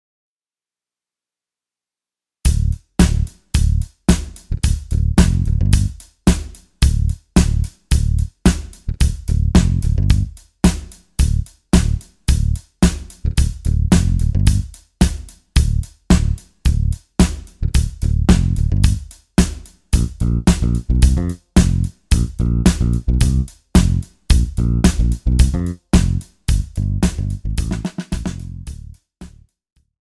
• Instrumentation : saxophone alto
• Genre : pop et rock
• Style : pop